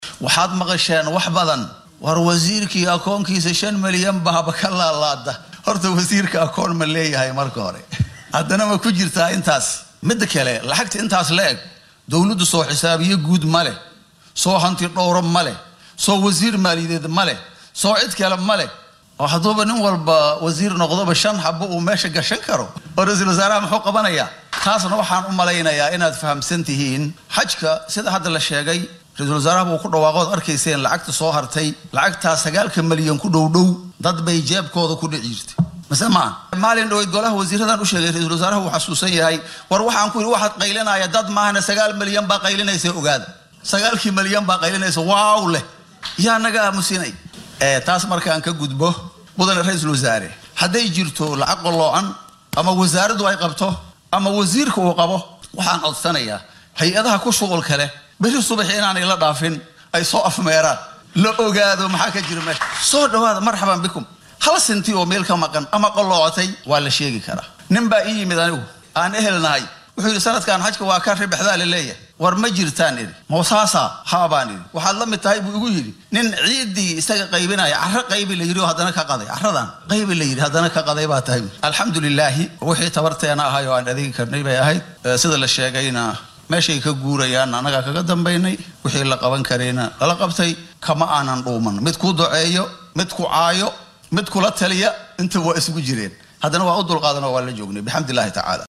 Wasiirka diinta iyo awqaafta ee xukuumadda Soomaaliya, Sheekh Mukhtaar Roobow Cali (Abuu Mansuur) oo xalay khudbad ka jeediyay munaasabad lagu soo gunaanadayay howlaha xajka ee sanadkii tegay ayaa jawaab rasmi ah ka bixiyay eedhaha musuq ee loo jeediyay, ka dib markii la sheegay inay akoonkiisa ay ku dhacday lacag dhan ilaa 5 milyan oo doolar, taas oo si aad ah loogu baahiyay baraha bulshada. Roobow ayaa beeniyay in lacagtaasi ay ku dhacday jeebkiisa.